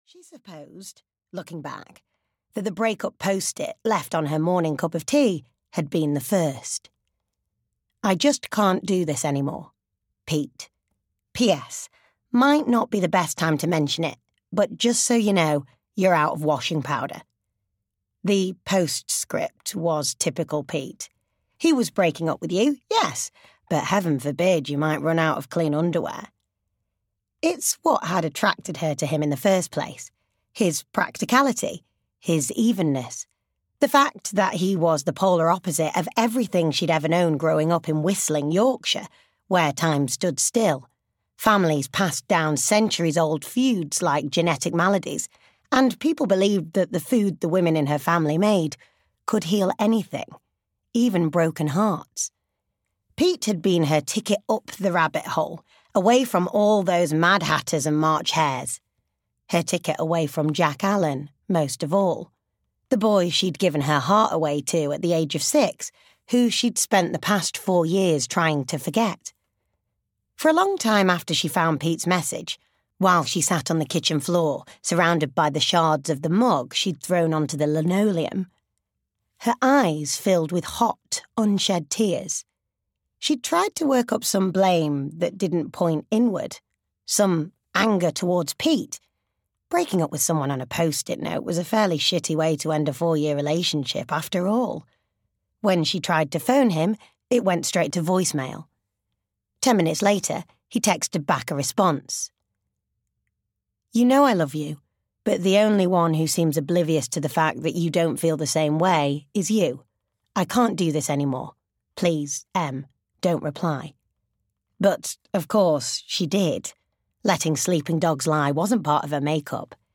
Christmas at Hope Cottage (EN) audiokniha
Ukázka z knihy